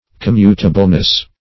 Search Result for " commutableness" : The Collaborative International Dictionary of English v.0.48: Commutableness \Com*mut"a*ble*ness\, n. The quality of being commutable; interchangeableness.